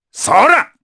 Baudouin-Vox_Attack3_jpb.wav